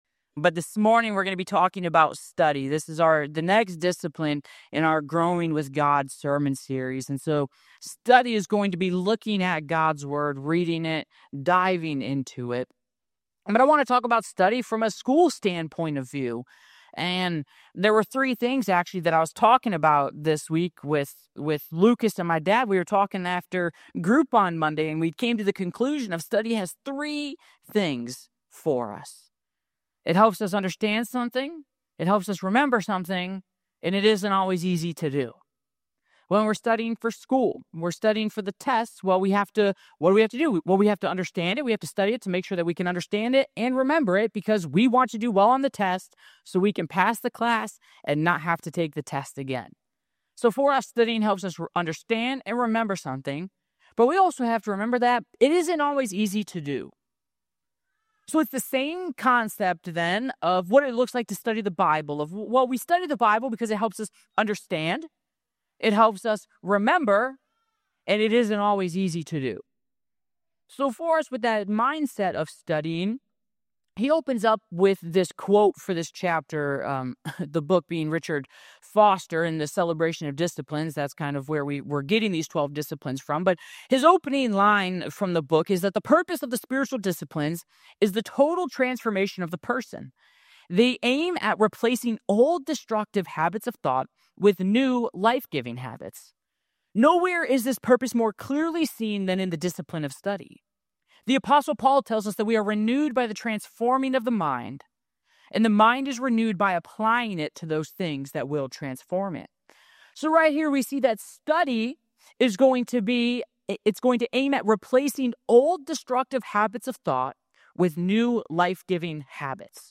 Study is part 4 of a 12-week Sermon Series about growing your faith and relationship with God.